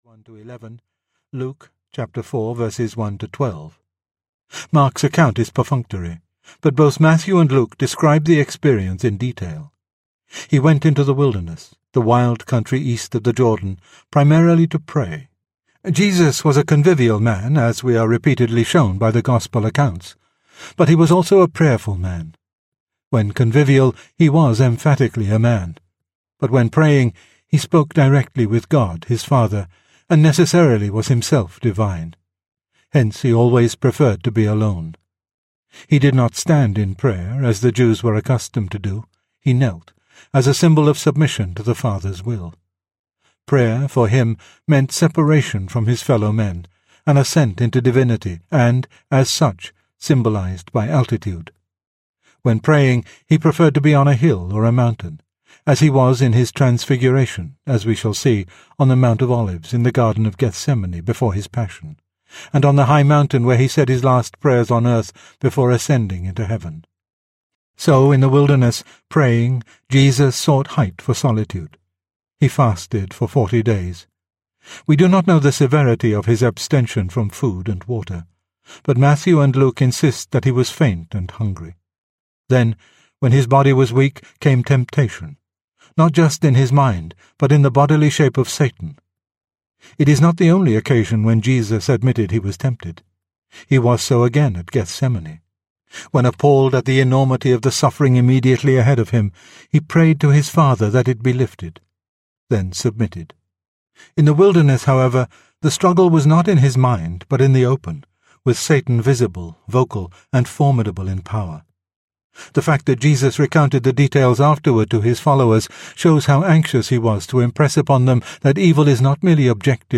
Jesus Audiobook